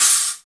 20 CRASH.wav